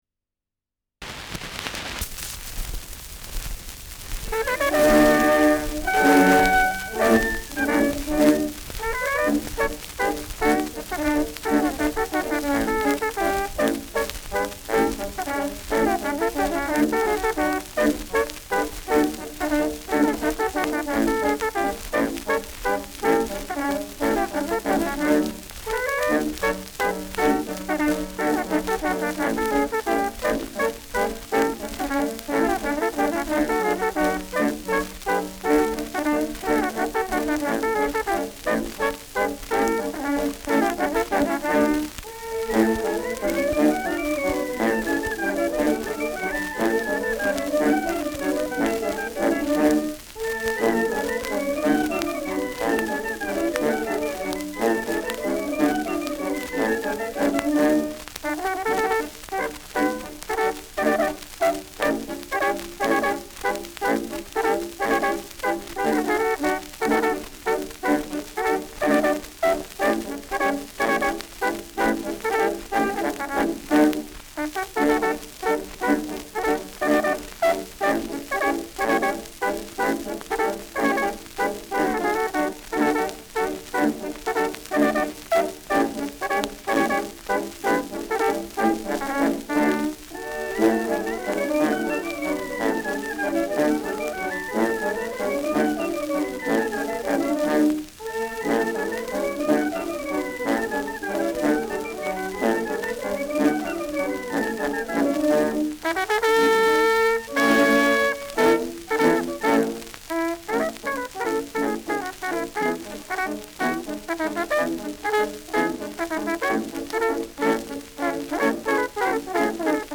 Schellackplatte
Stärkeres Grundknistern : Vereinzelt leichtes bis stärkeres Knacken : Nadelgeräusch
Stadtkapelle Fürth (Interpretation)